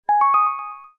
PopUp.aac